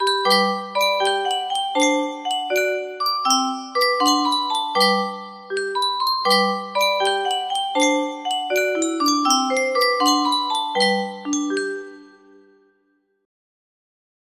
Clone of Sankyo Music Box - Where Has My Little Dog Gone iA music box melody
Yay! It looks like this melody can be played offline on a 30 note paper strip music box!